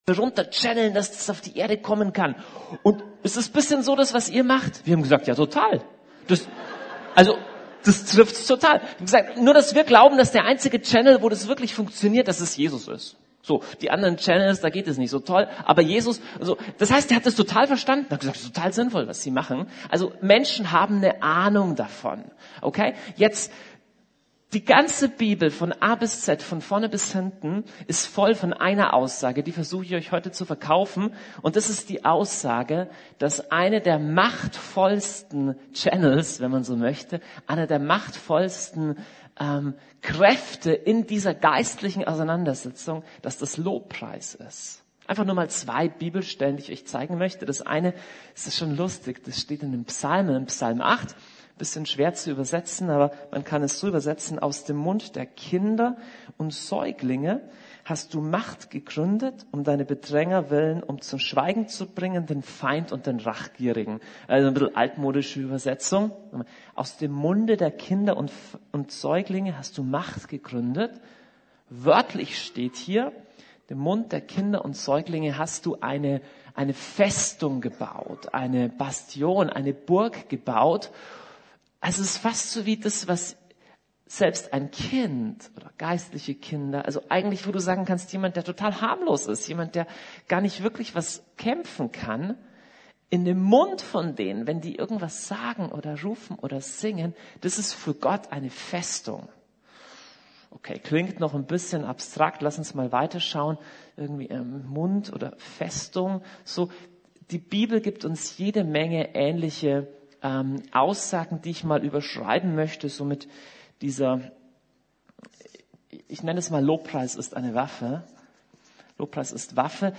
Ein Feuer, das nicht mehr erlischt! ~ Predigten der LUKAS GEMEINDE Podcast